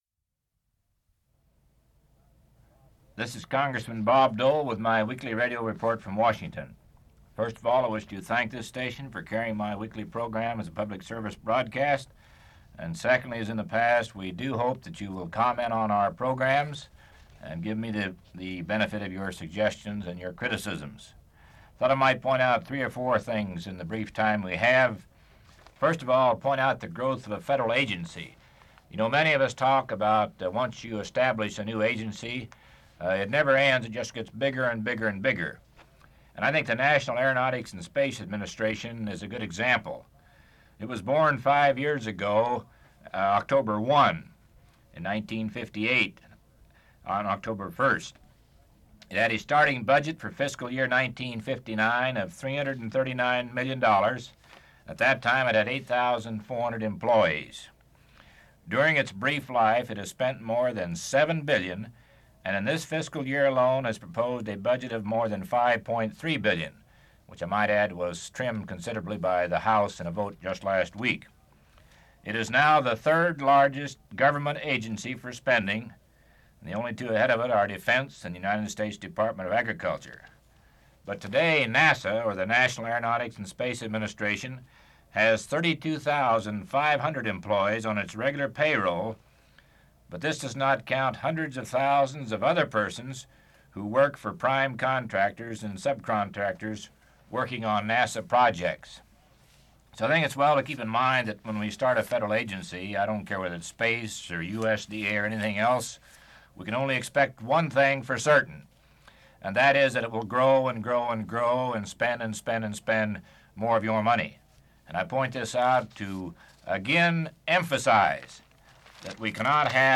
Part of Weekly Radio Report: NASA & Wheat Sales to the Soviet Union